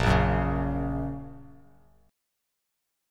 A#m#5 chord